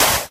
sand2.ogg